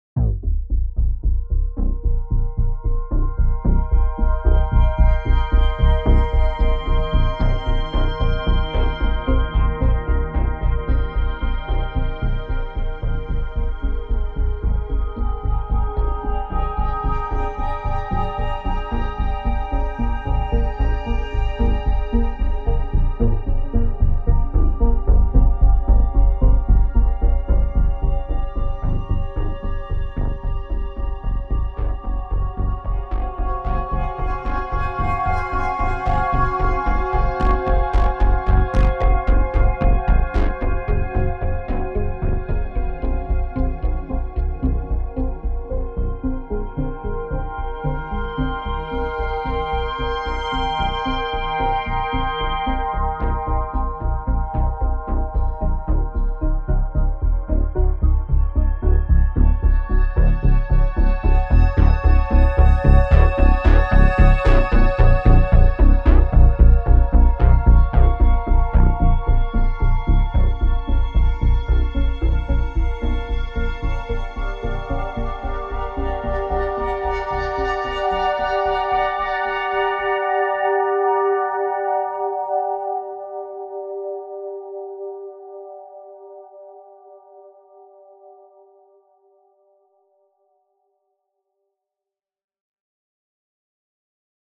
Here a little test with my synth-presents to self.